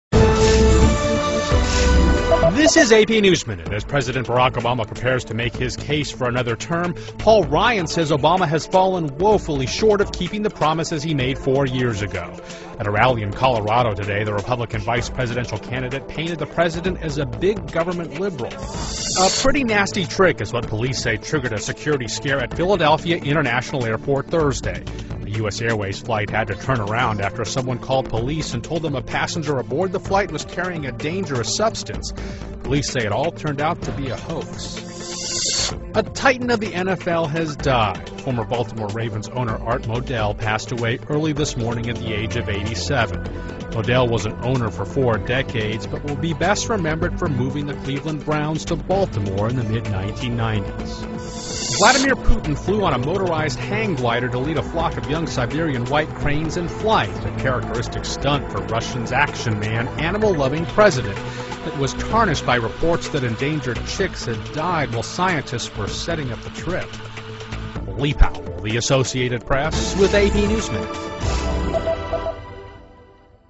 在线英语听力室美联社新闻一分钟 AP 2012-09-10的听力文件下载,美联社新闻一分钟2012,英语听力,英语新闻,英语MP3 由美联社编辑的一分钟国际电视新闻，报道每天发生的重大国际事件。电视新闻片长一分钟，一般包括五个小段，简明扼要，语言规范，便于大家快速了解世界大事。